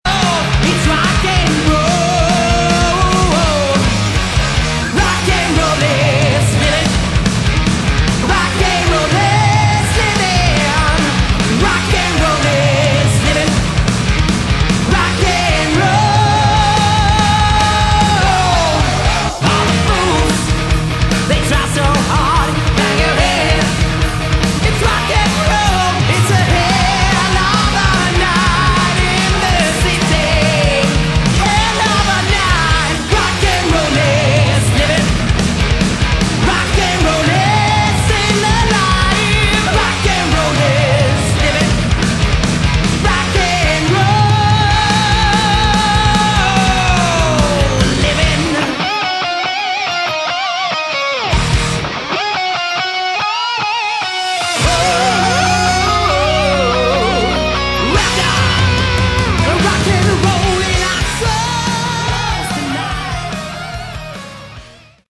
Category: Melodic Metal
This is 80s METAL.